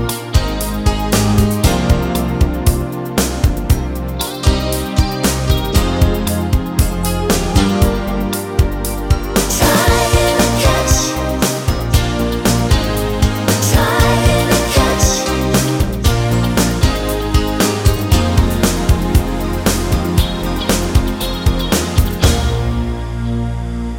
For Duet Pop (1980s) 4:31 Buy £1.50